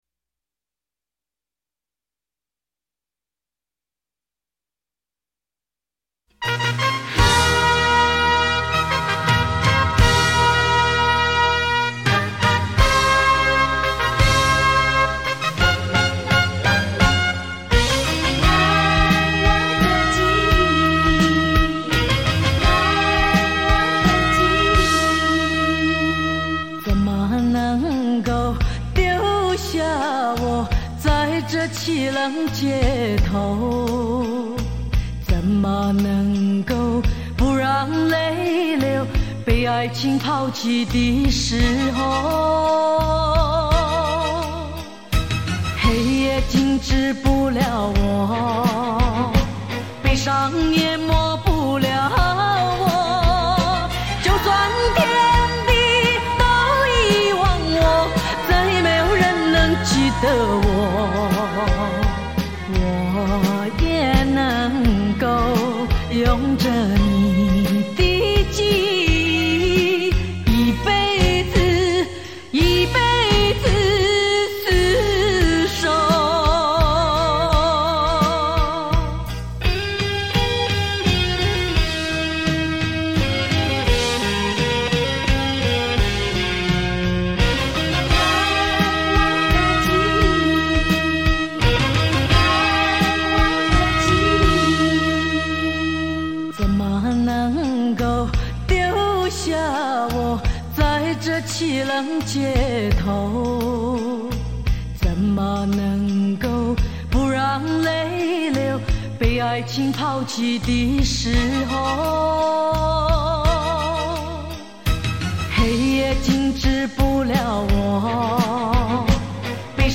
类      别：录音室专辑